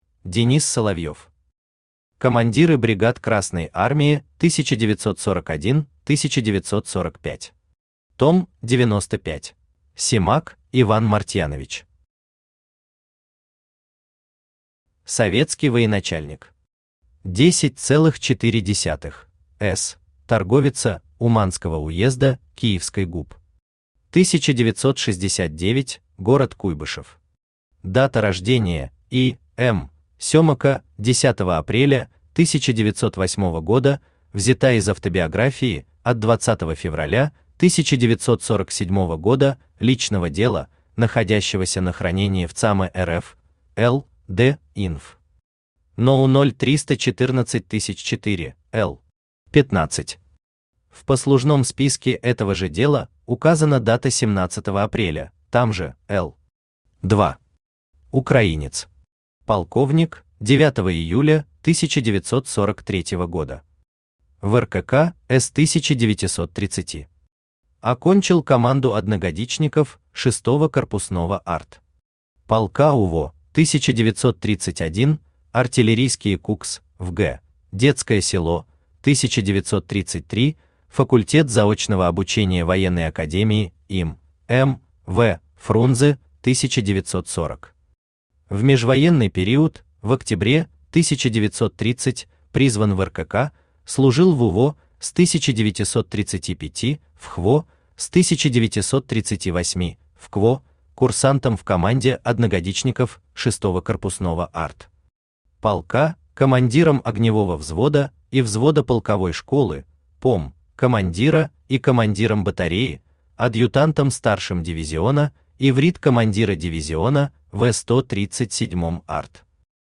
Аудиокнига Командиры бригад Красной Армии 1941-1945. Том 95 | Библиотека аудиокниг
Том 95 Автор Денис Соловьев Читает аудиокнигу Авточтец ЛитРес.